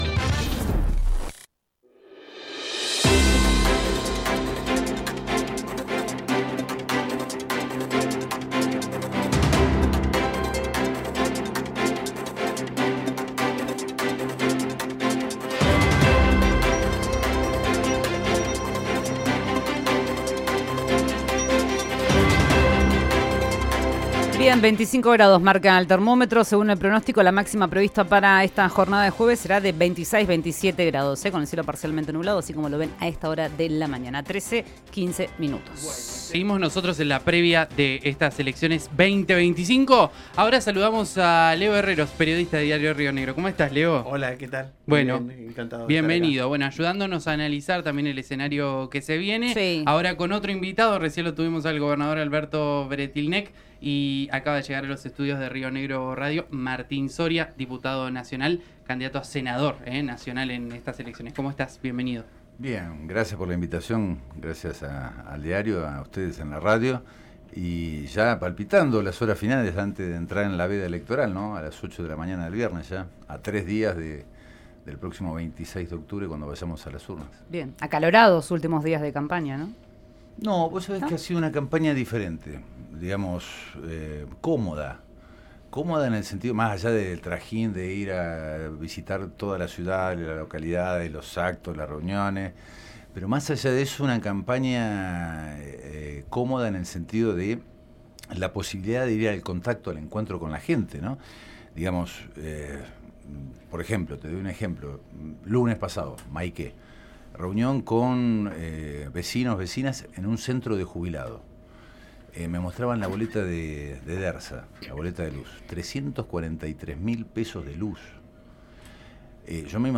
El diputado y candidato a senador por Fuerza Patria estuvo en los estudios de RÍO NEGRO RADIO.
El diputado nacional y candidato a senador por Fuerza Patria en Río Negro, Martín Soria, analizó el panorama electoral a horas de la veda en una entrevista en RÍO NEGRO RADIO y evaluó que existe una nacionalización del debate y que no observa “una política de tercios” en la provincia.